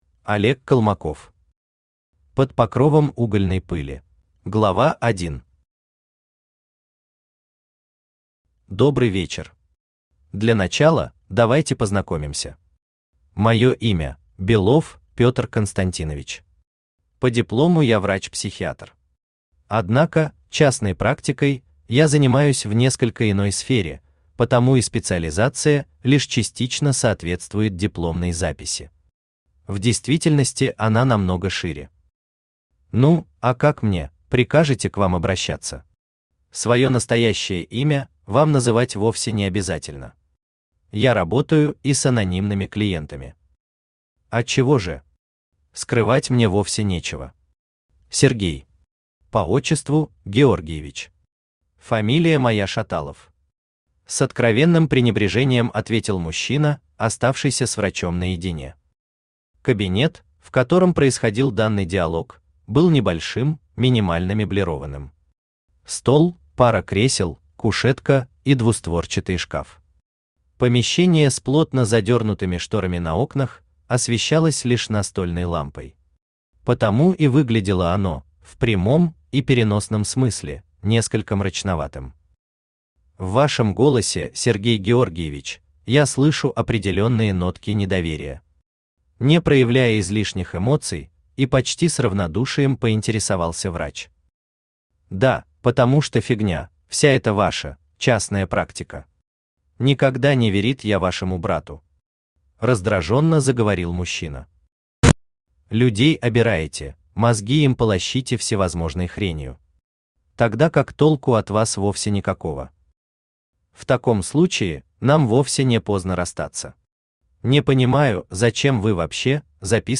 Аудиокнига Под покровом угольной пыли | Библиотека аудиокниг
Aудиокнига Под покровом угольной пыли Автор Олег Колмаков Читает аудиокнигу Авточтец ЛитРес.